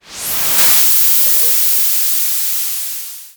GasReleasing02.wav